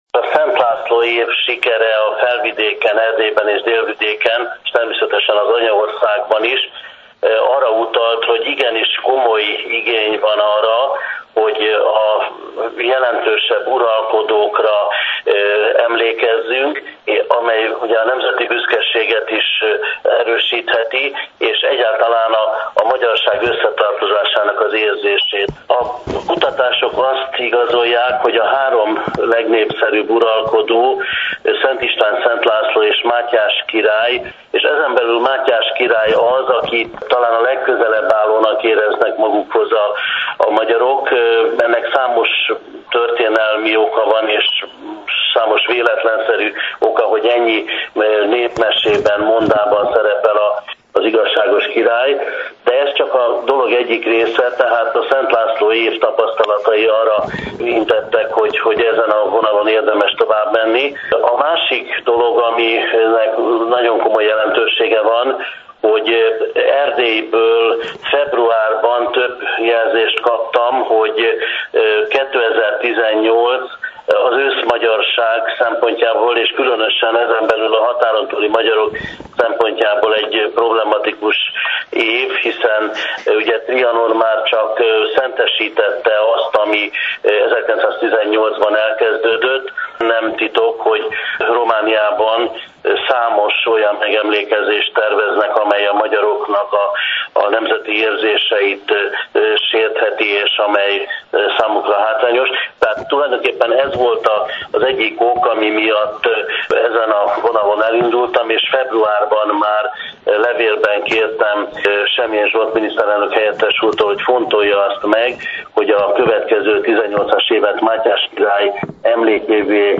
A következő interjút a Torontói Független Magyar Rádiótól kaptuk
Hallgassák meg az alábbi linkre kattintva a teljes beszélgetést.